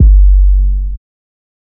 Waka 808 - 2 (2).wav